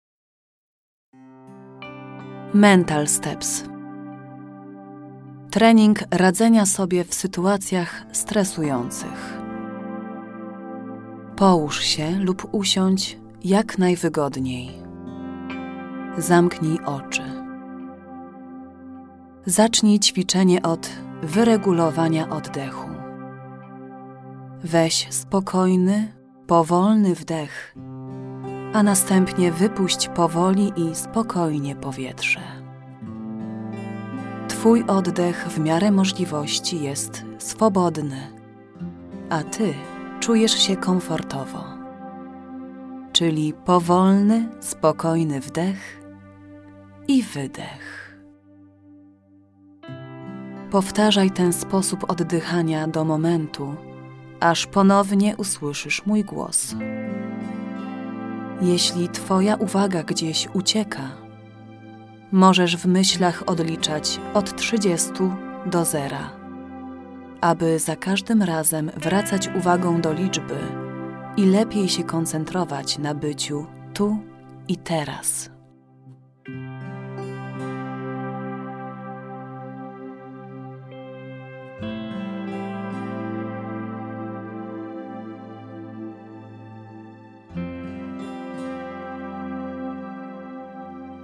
🔊 Ćwiczenie oddechowe (w tym ćwiczenie „oddech po trójkącie”)
🔊 Przez nagranie prowadzi Cię głos profesjonalnej aktorki
To nagranie prowadzi Cię krok po kroku — spokojnie i profesjonalnie.